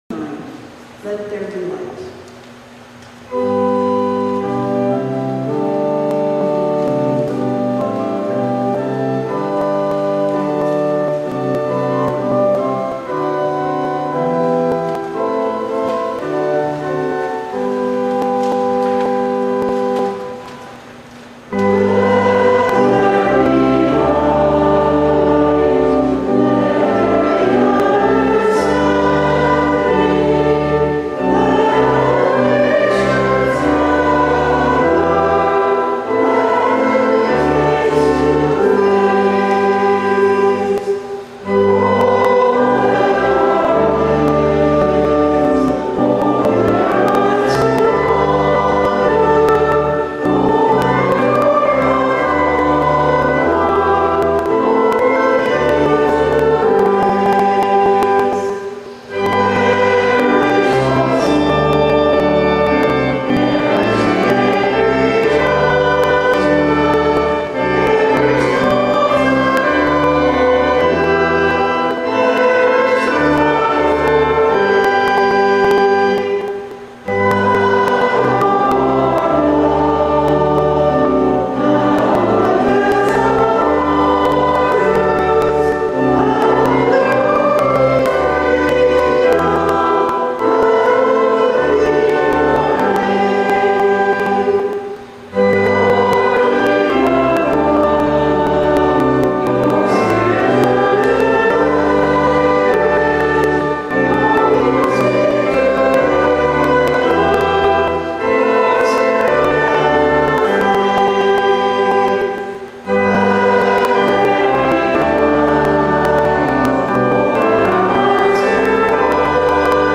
HYMN: Frances Wheeler Davis
hymn-679-let-there-be-light-2.mp3